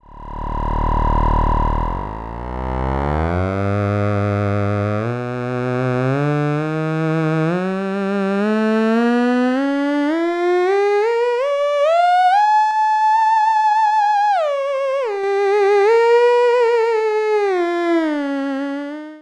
My single tube/valve theremin-like design is evolving.
When my tube/valve sound begins to chirp like the bug it points the way to a heterodyning combination which will serenade with an authentic sound.
I left in this odd sound at the start of this sound byte so you know what I am talking about.
cicada-demo.wav